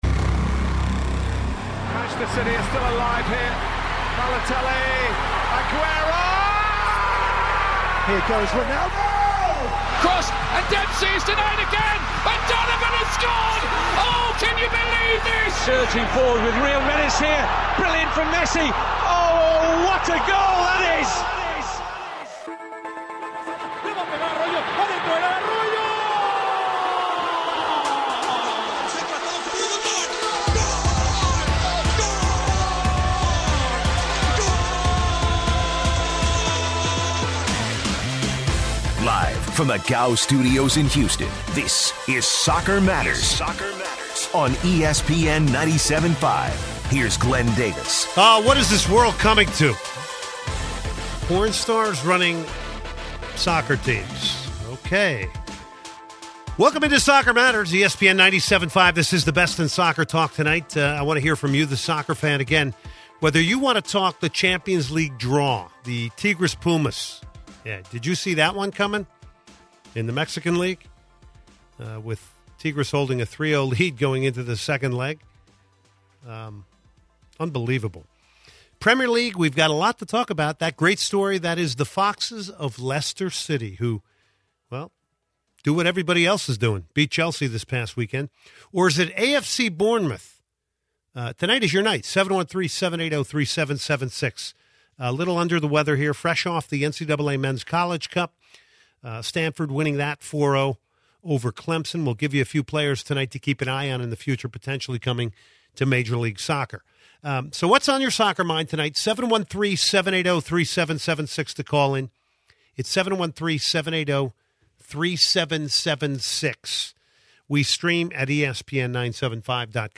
takes calls from listeners